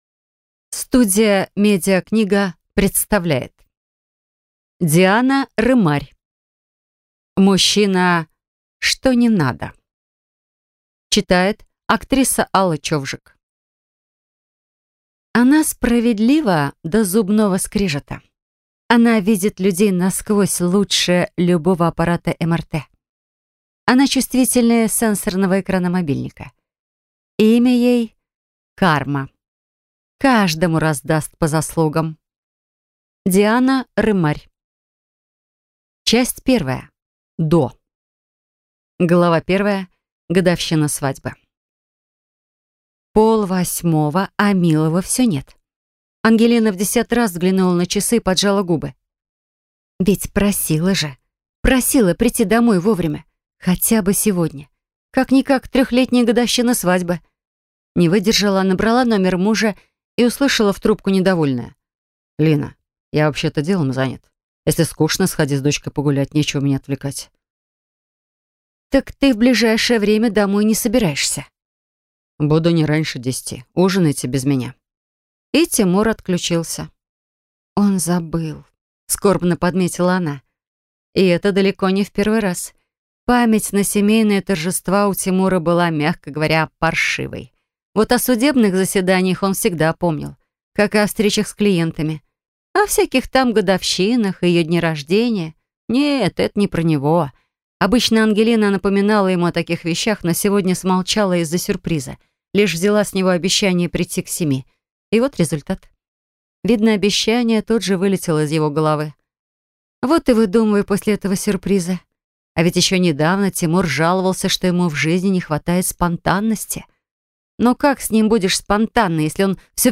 Аудиокнига Мужчина что (не) надо | Библиотека аудиокниг